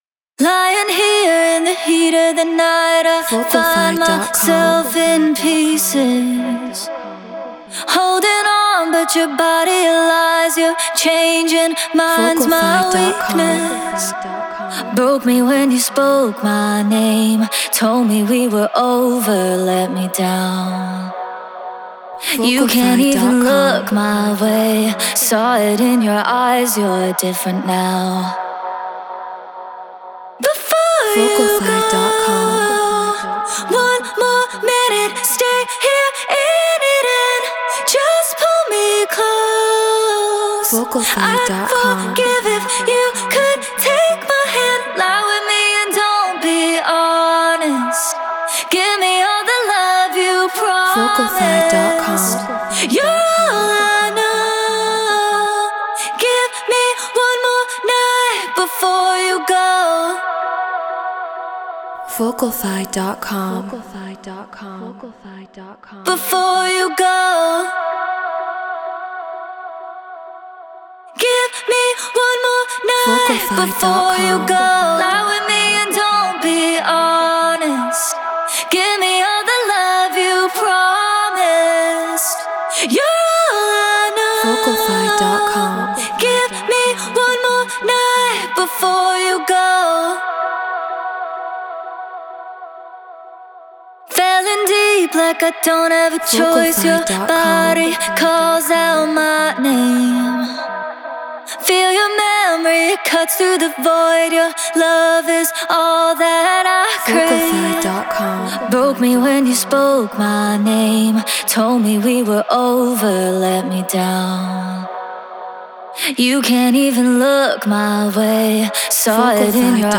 Prog House 128 BPM Emaj
Treated Room